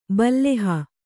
♪ balleha